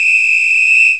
COPWHISL.mp3